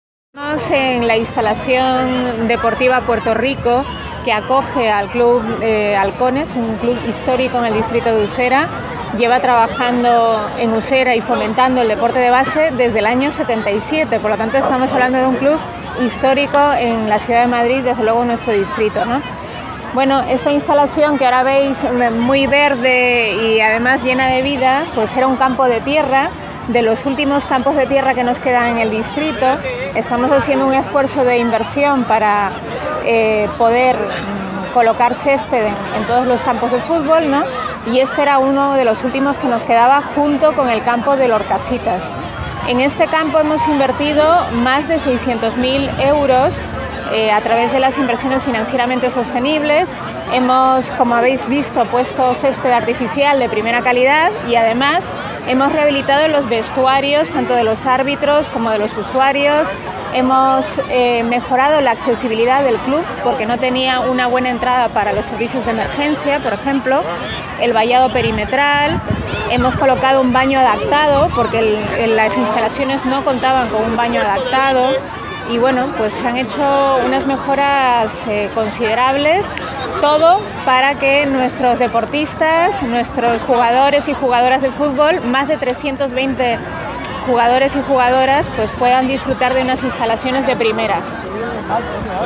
Nueva ventana:Declaraciones de la concejala de Usera, Rommy Arce, en la renovada instalación básica deportiva Puerto Rico, en el Poblado Dirigido de Orcasitas